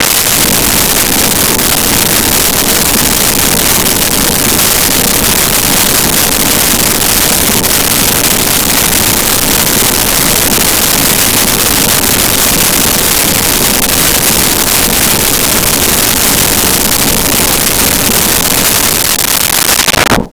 Engine Loop
Engine Loop.wav